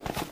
STEPS Dirt, Run 27.wav